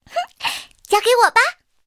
SU-26编入语音.OGG